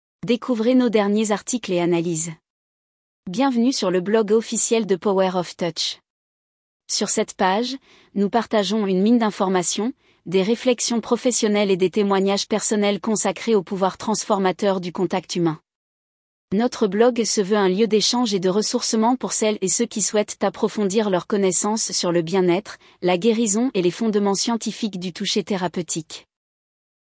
mp3-text-to-voice-perspectives-power-of-touch.mp3